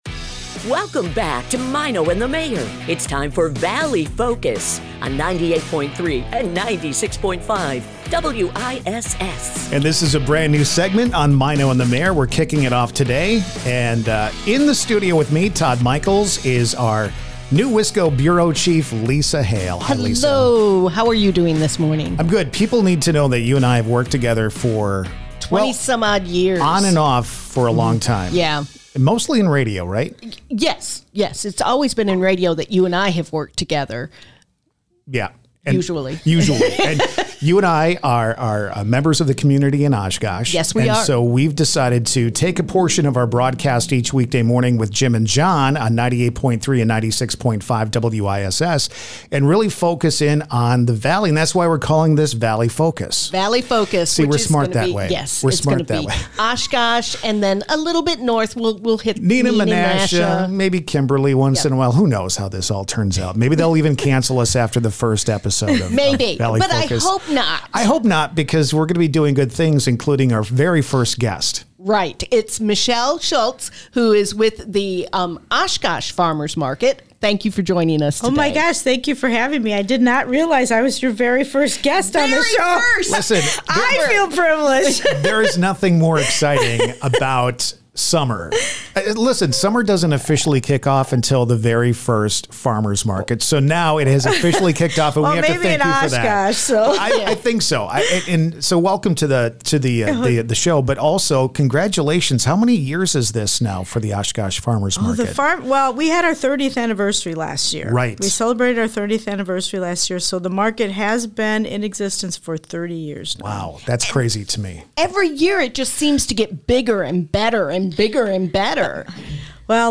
Valley Focus is a part of the Civic Media radio network and airs weekday mornings at 6:50 a.m. as part of the Maino and the Mayor Show on 96.5 and 98.3 WISS in Appleton and Oshkosh.